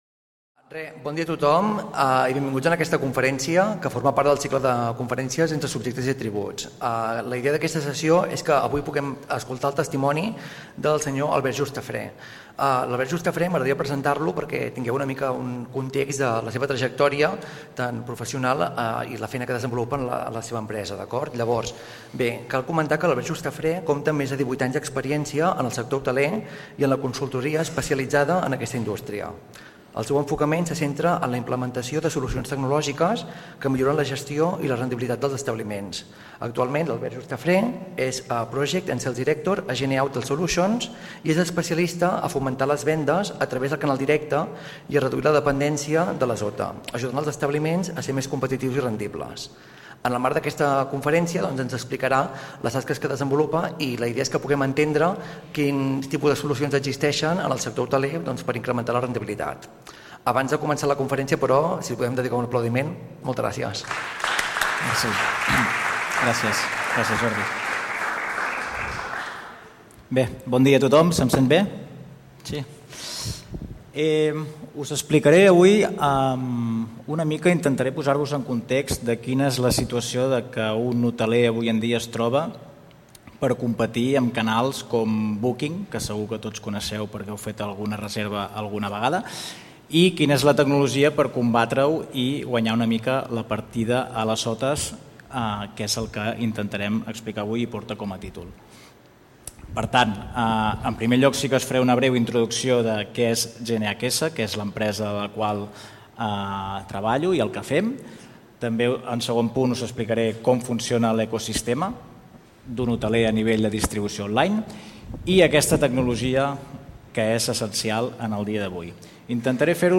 En aquesta conferència